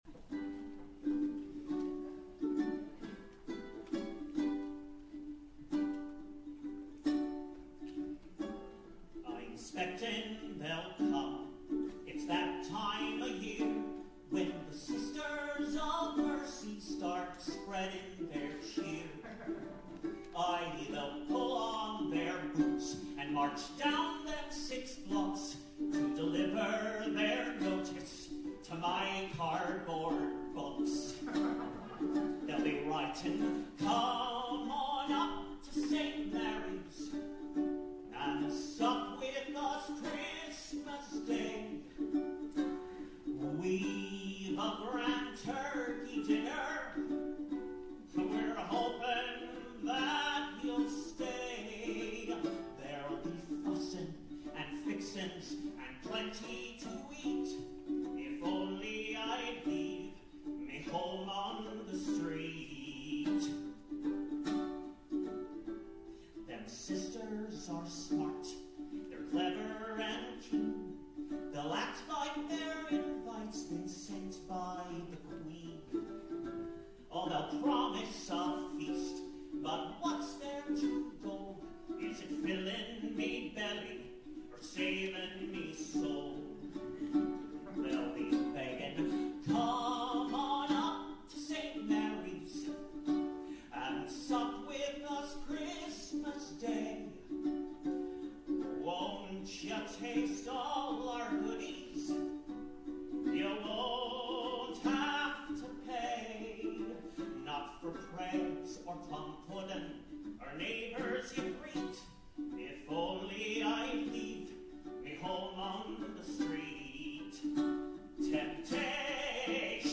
Cabaret Songs
Ukulele